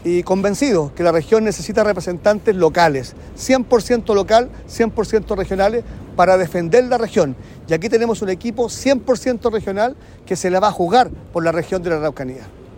El presidente del partido en La Araucanía, diputado Henry Leal, dijo que trabajarán unidos y convencidos de que la región necesita representantes locales.